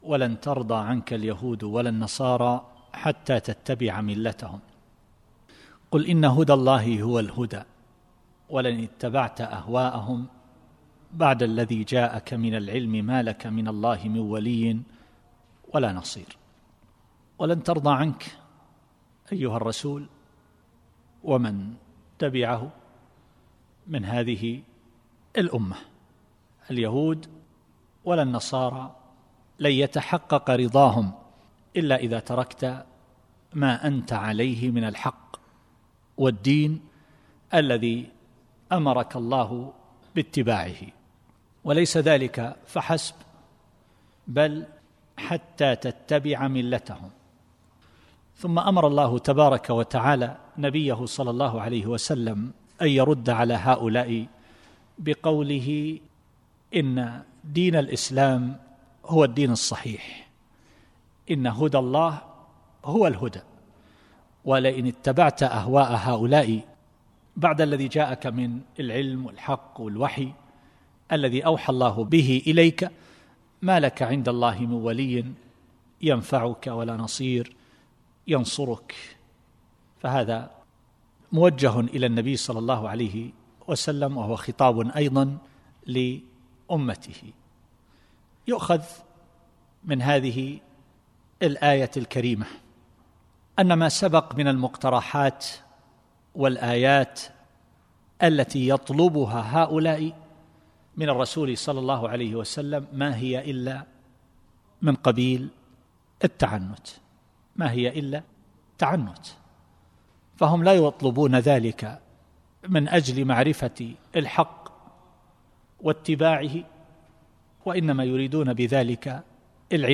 التفسير الصوتي [البقرة / 120]